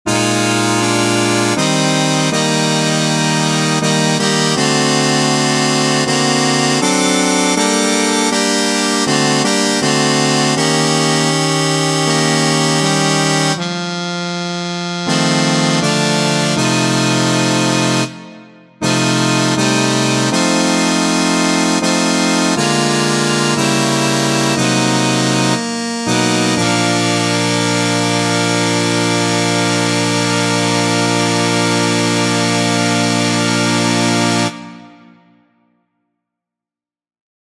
Key written in: B♭ Major
How many parts: 4
Type: Barbershop
All Parts mix: